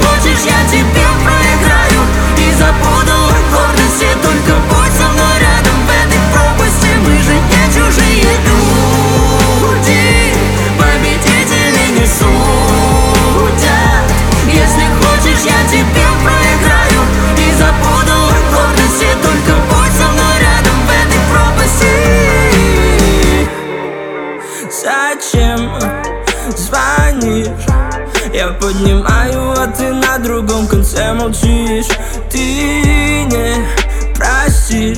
Жанр: Русская поп-музыка / Поп / Русские